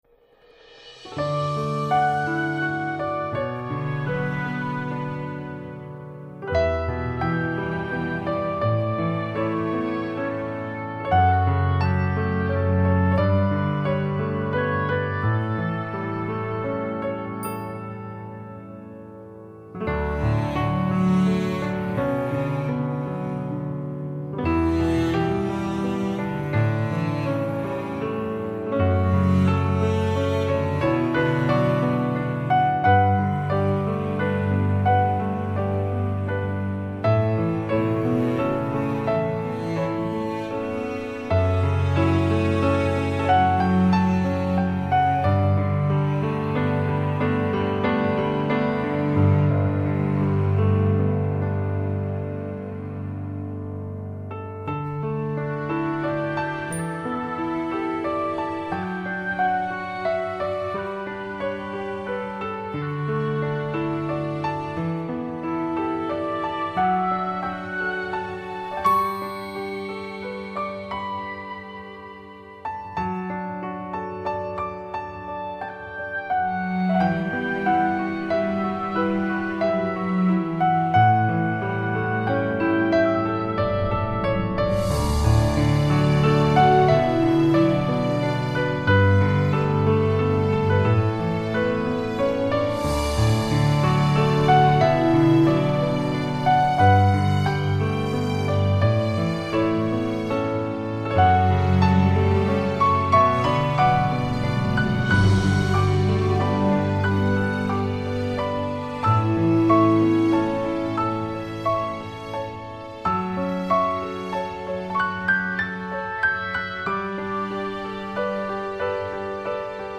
音乐风格: New Age / Piano